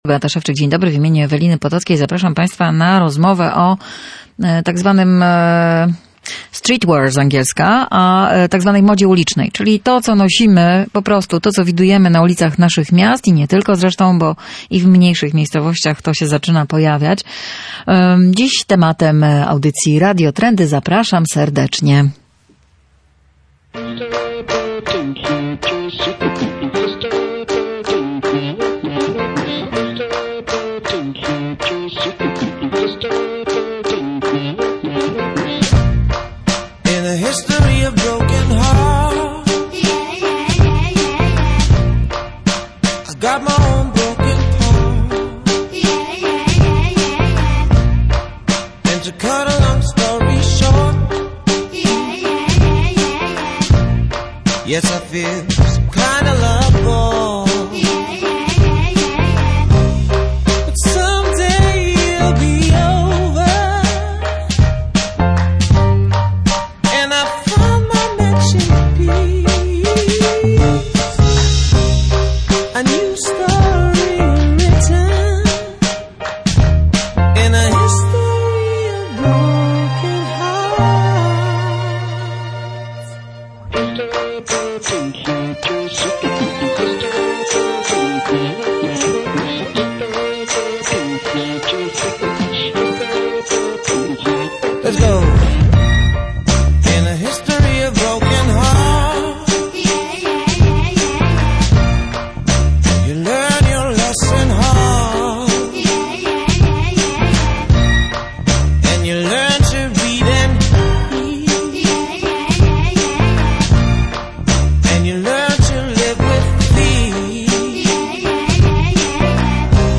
W audycji Radio Trendy rozmawiamy też o pokoleniu millenialsów oraz o polskich markach modowych, które wybiły się za granicą. Będzie też o ekologii w modzie i modzie na postsowietyzm.